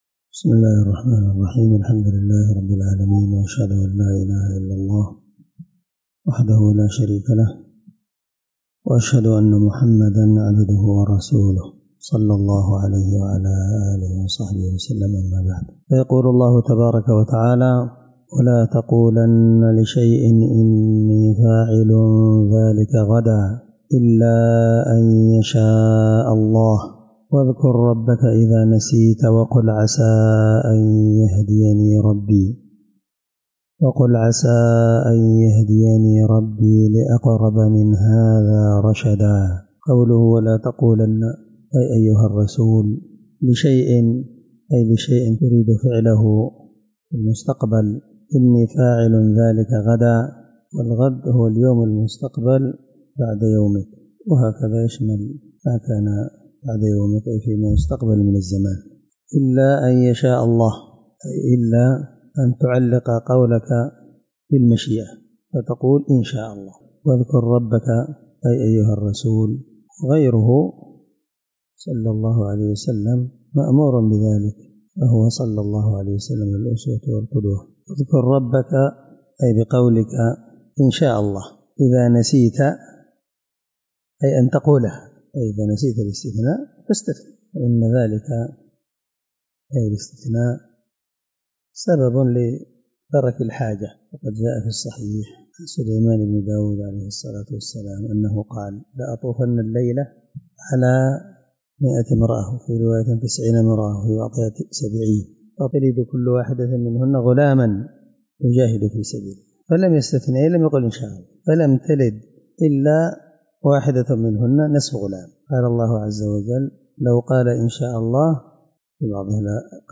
الدرس10 تفسير آية (23-24) من سورة الكهف
18سورة الكهف مع قراءة لتفسير السعدي